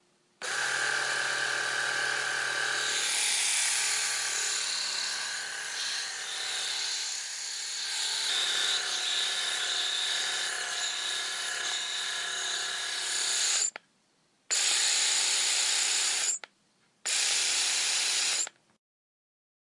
剃刀
描述：一个男士剃须刀，用于剃胡须，也只是剃须刀的声音。
Tag: 剃须 人为剃须 人为剃须刀 OWI